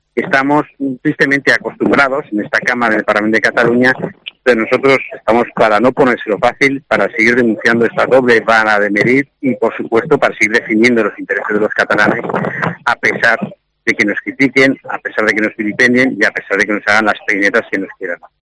Declaración: Joan Garriga, portavoz del grupo parlamentario Vox